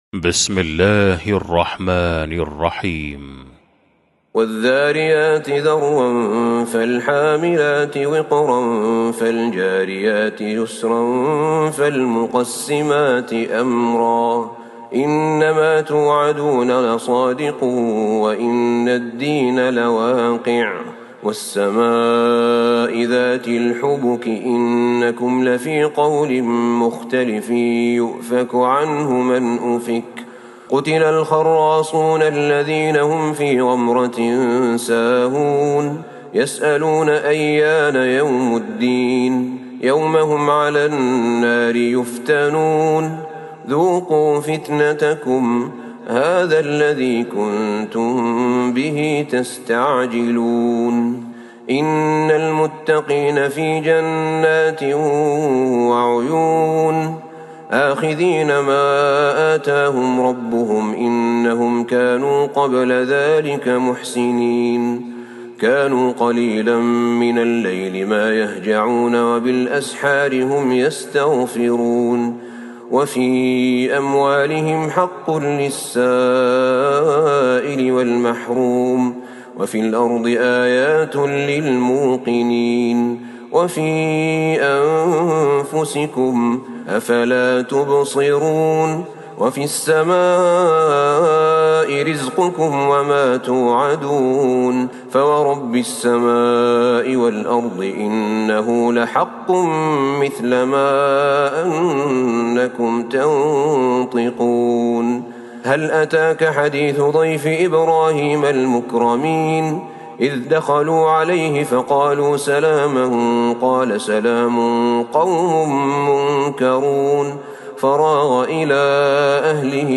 سورة الذاريات Surat Adh-Dhariyat > مصحف تراويح الحرم النبوي عام ١٤٤٣ > المصحف - تلاوات الحرمين